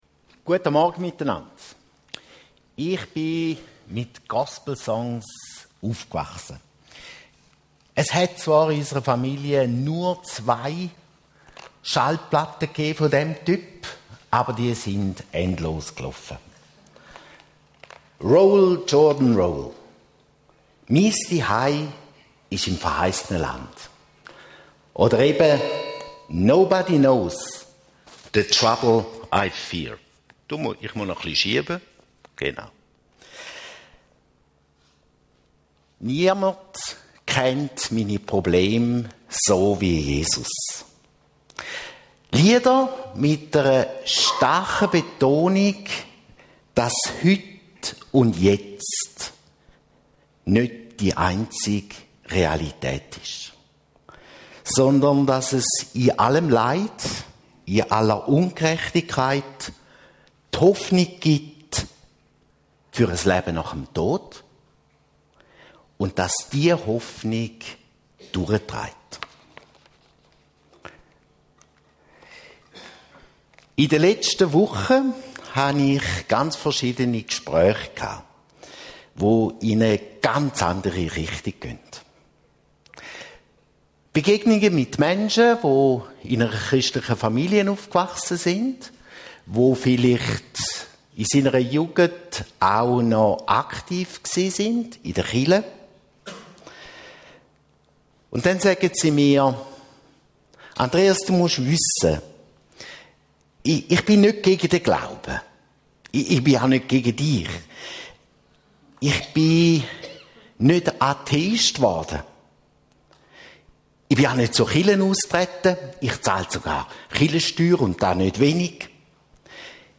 Gospel Gottesdienst mit AdHoc Chor Kulm